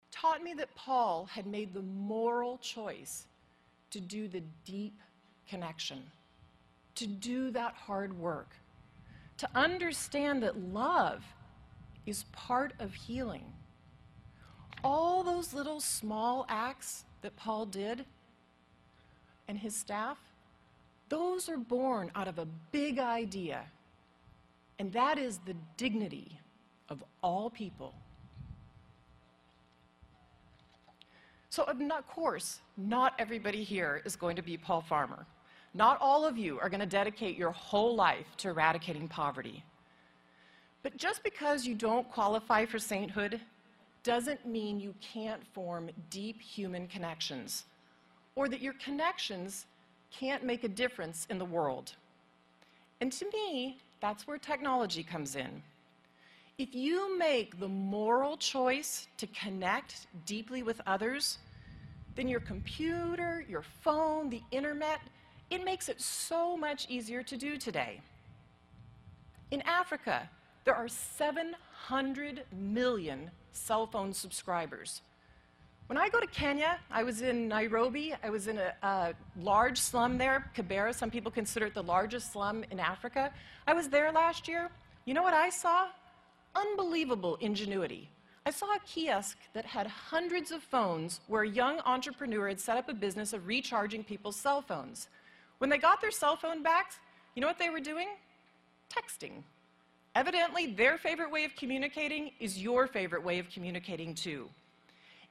公众人物毕业演讲第348期:梅琳达2013在杜克大学(7) 听力文件下载—在线英语听力室